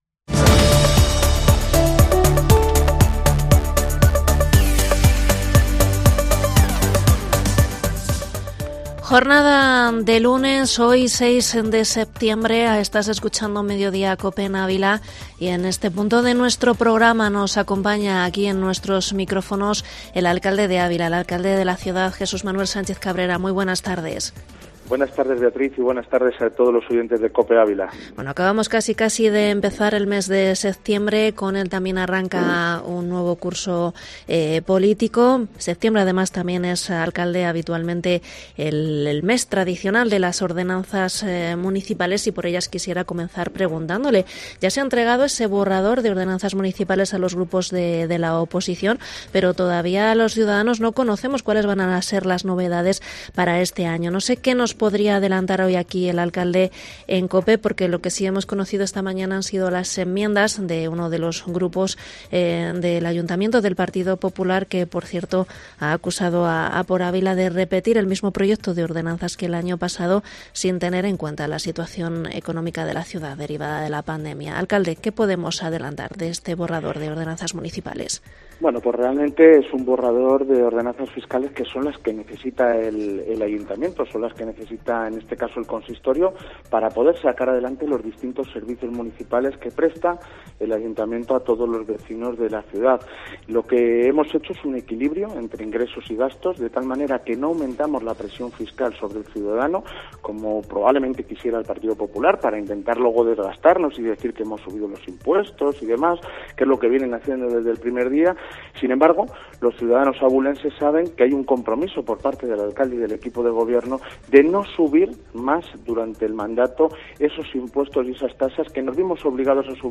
Entrevista alcalde de Ávila en Mediodía COPE ÁVILA 6/09/2021